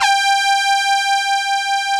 Index of /90_sSampleCDs/Club-50 - Foundations Roland/SYN_xAna Syns 1/SYN_xJX Brass X2